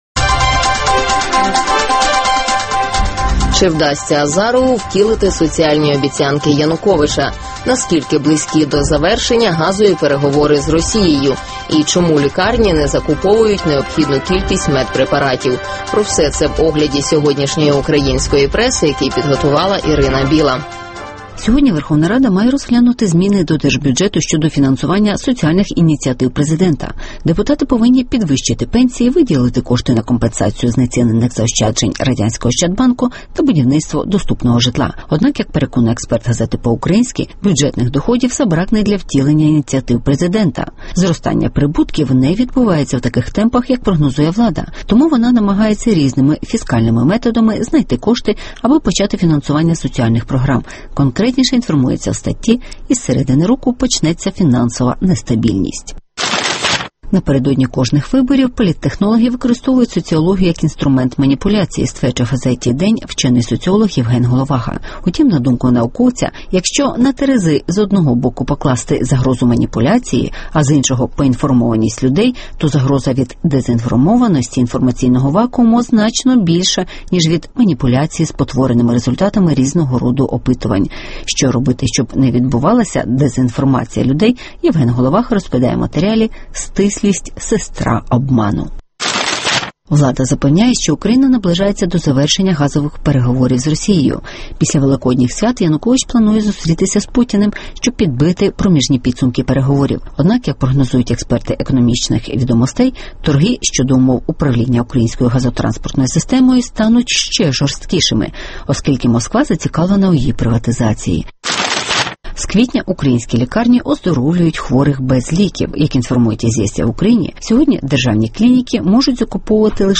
Клінічний випадок в українських лікарнях (огляд преси)